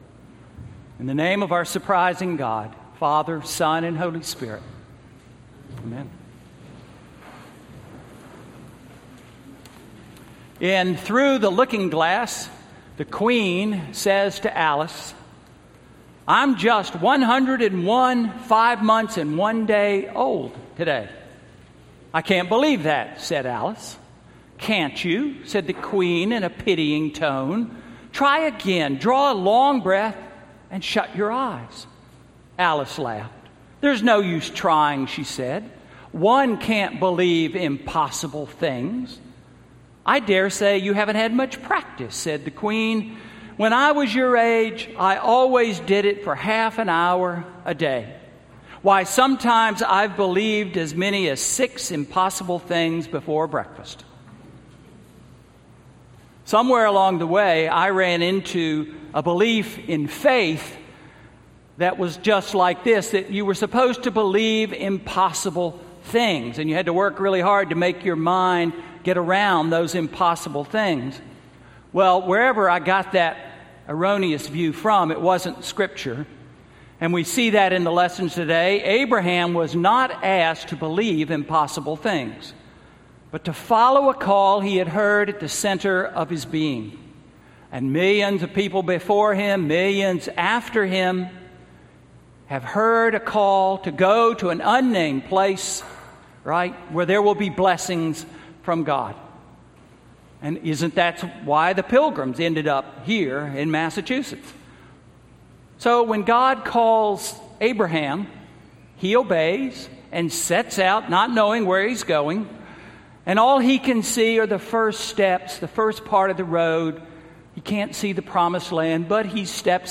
Sermon–August 7, 2016